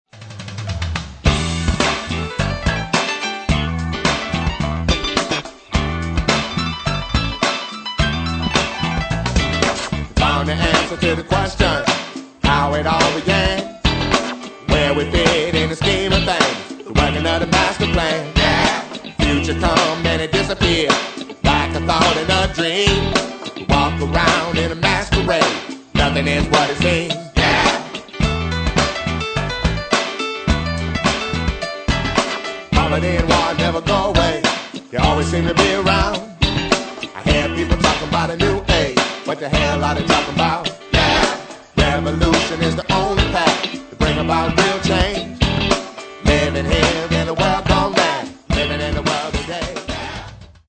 Hip Hop, Reggae, Funk, Latin Music, World Music
ultra-funky